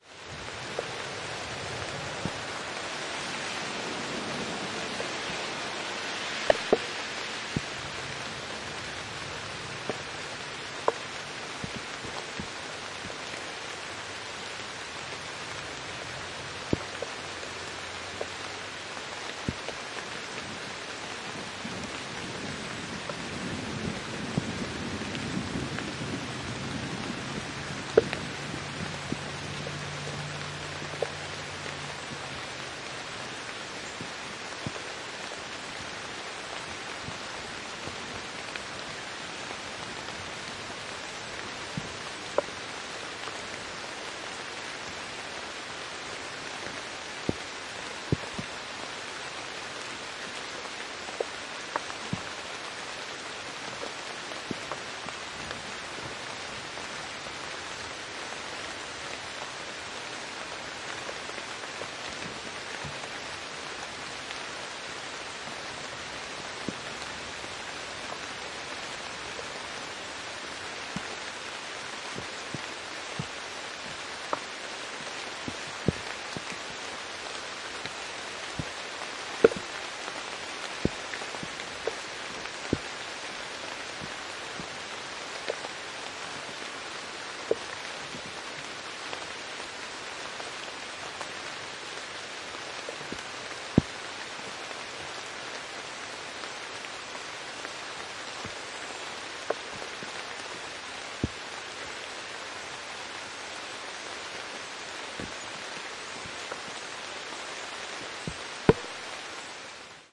时钟 " 古老的钟声
Tag: 祖父时钟 滴答 时间流逝 19世纪 时钟 古董